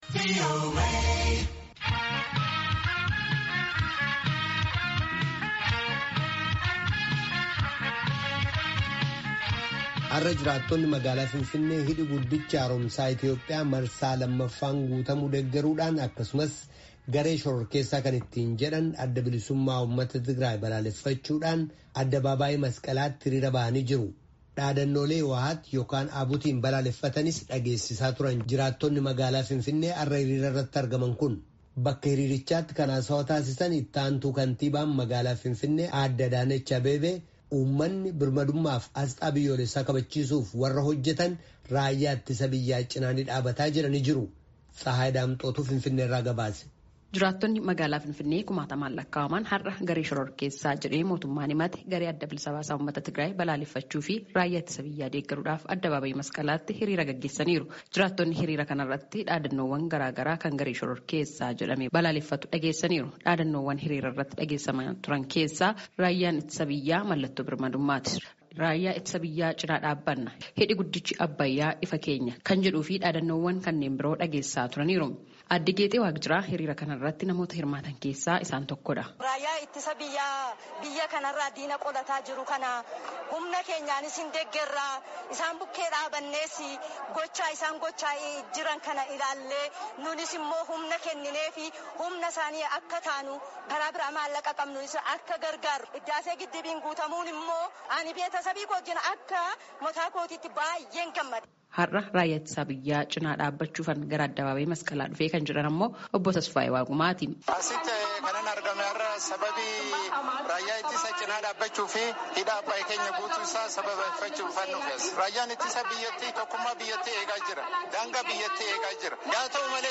Dhaadannoolee HIWEHAT yokaan ABUTn balaaleffatan dhageessisaa turan, jiraattonni magaalaa Finfinnee hiriira har’aa irratti argaman.